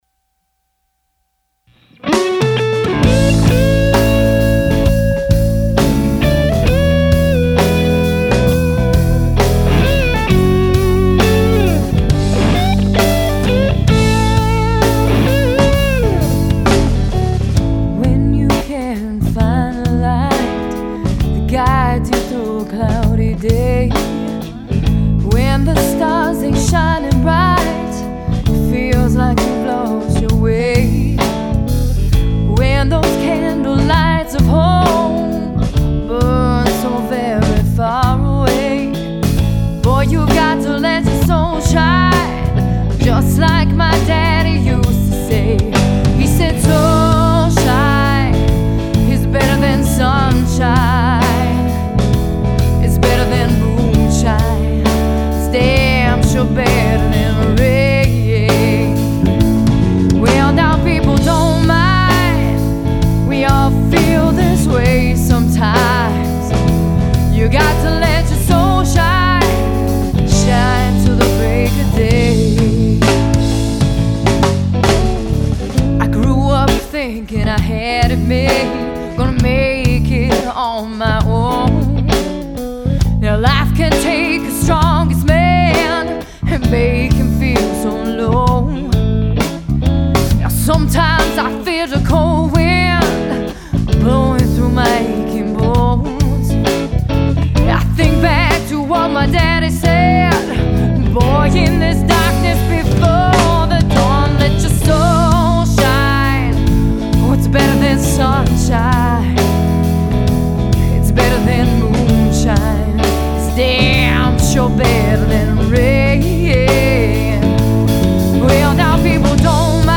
female fronted
blues/rock
energetic presence